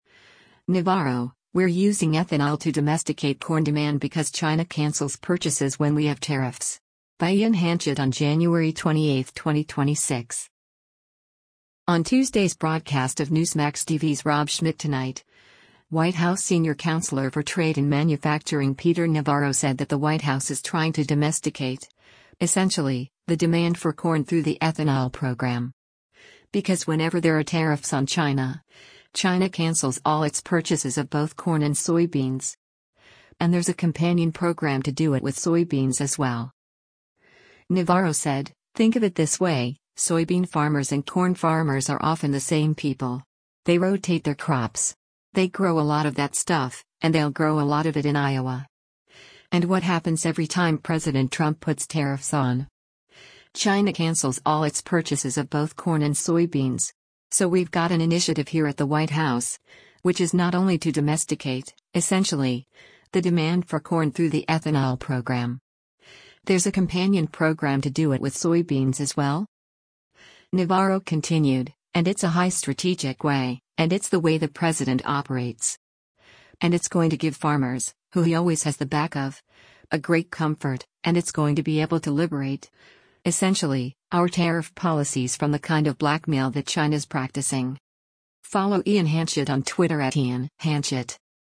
On Tuesday’s broadcast of Newsmax TV’s “Rob Schmitt Tonight,” White House Senior Counselor for Trade and Manufacturing Peter Navarro said that the White House is trying “to domesticate, essentially, the demand for corn through the ethanol program.” because whenever there are tariffs on China, China “cancels all its purchases of both corn and soybeans.”